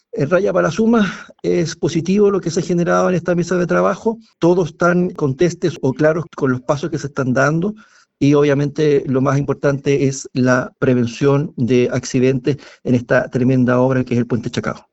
Por su parte, el seremi del MOP en la zona, Juan Alvarado, destacó la mesa de trabajo que se generó.